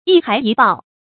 一還一報 注音： ㄧ ㄏㄞˊ ㄧ ㄅㄠˋ 讀音讀法： 意思解釋： 謂做一件壞事后必受一次報復。